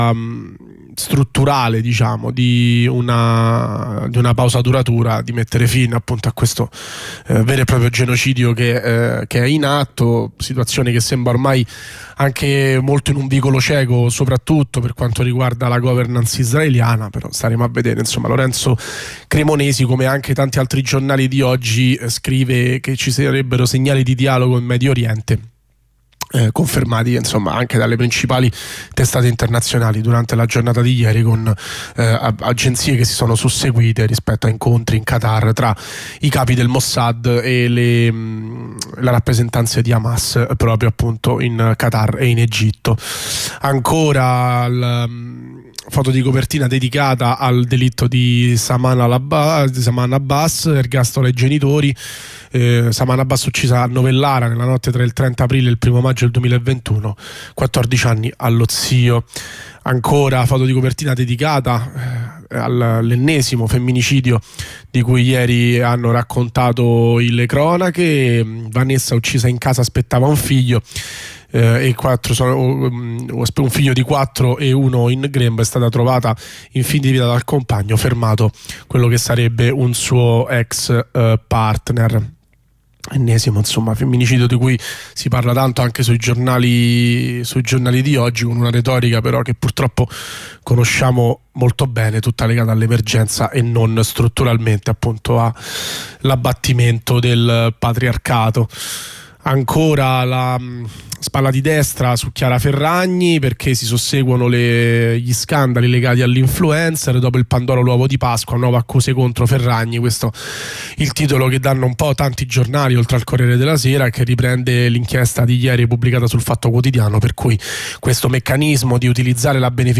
Rassegna stampa del 29 dicembre 2023